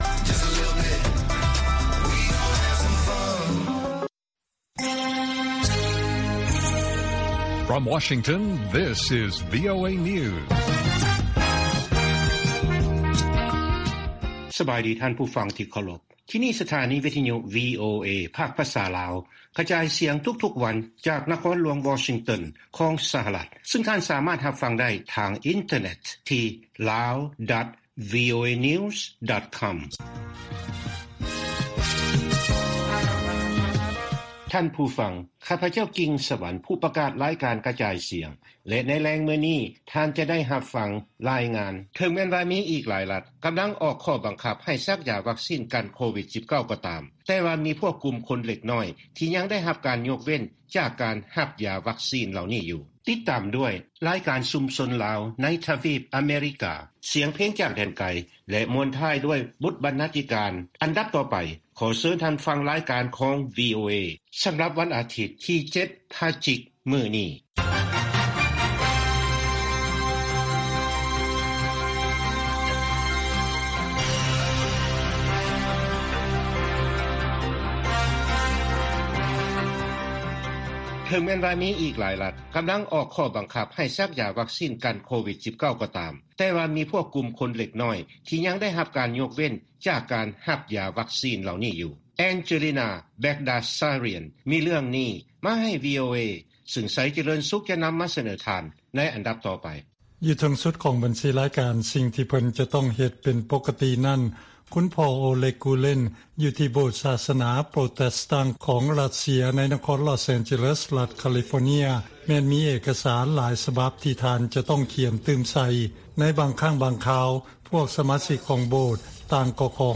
ລາຍການກະຈາຍສຽງຂອງວີໂອເອ ລາວ: ວີໂອເອລາວສຳພາດທ່ານນາງ